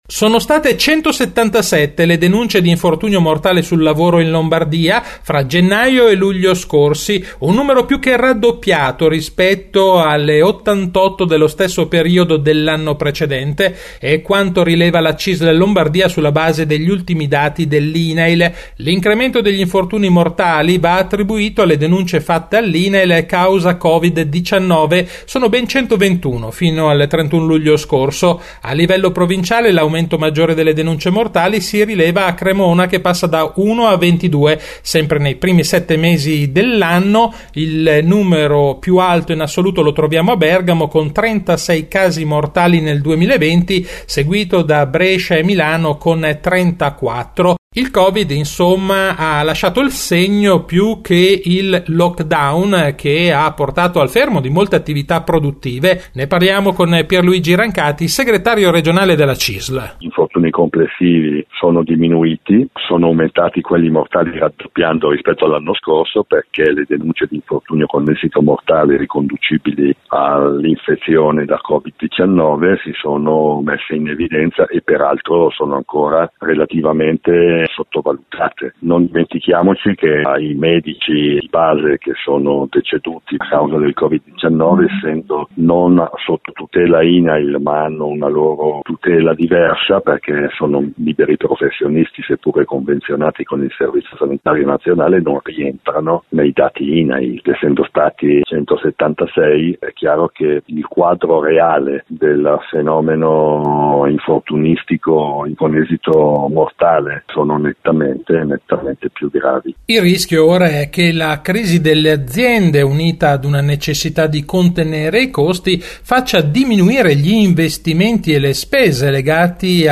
Di seguito la puntata del 3 settembre di RadioLavoro, la rubrica d’informazione realizzata in collaborazione con l’ufficio stampa della Cisl Lombardia e in onda ogni quindici giorni il giovedì alle 18.20 su Radio Marconi, in replica il venerdì alle 12.20.